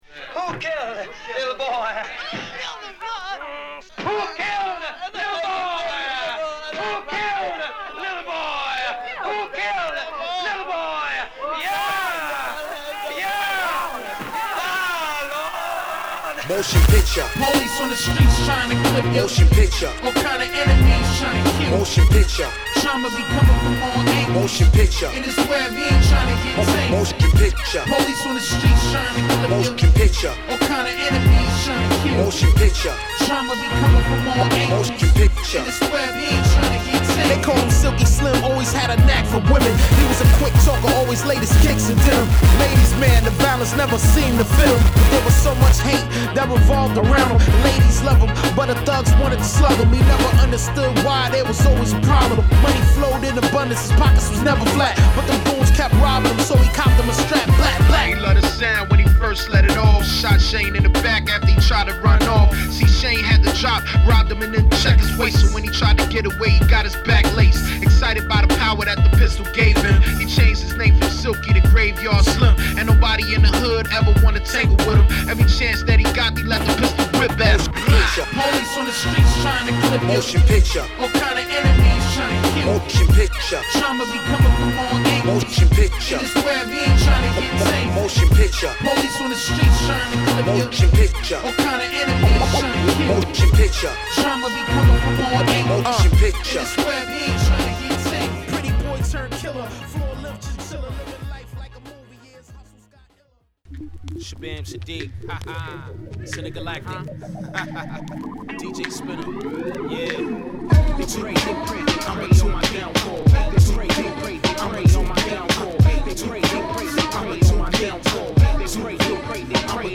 OSTからのサンプリングによるイントロから、インパクト大のホーンループ、攻撃的アタック音